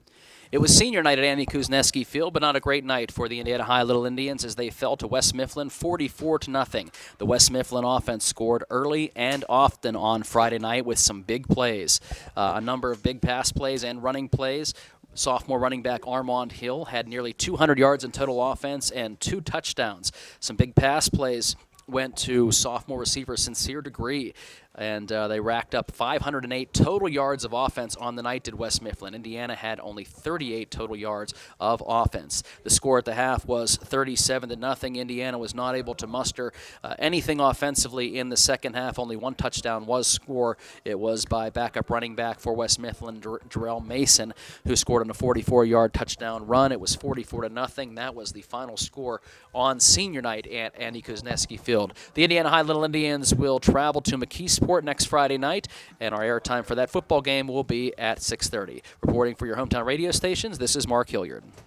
hsfb-indiana-vs-west-mifflin-recap.wav